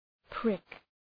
Shkrimi fonetik {prık}